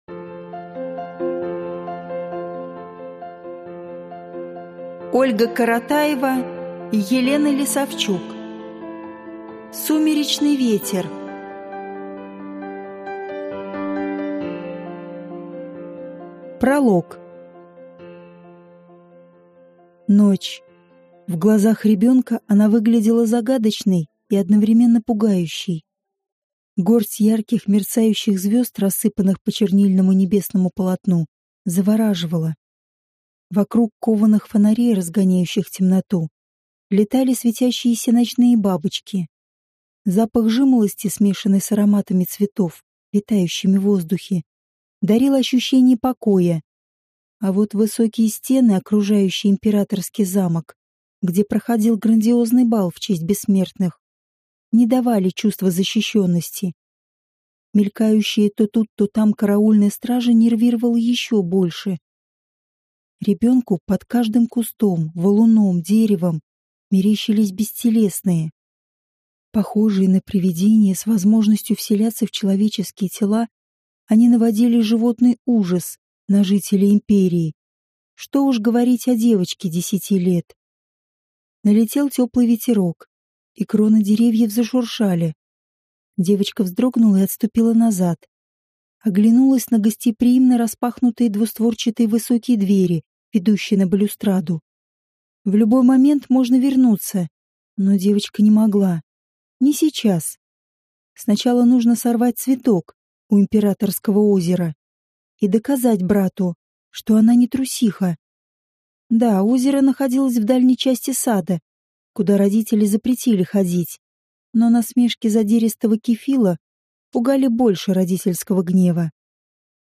Аудиокнига Сумеречный ветер | Библиотека аудиокниг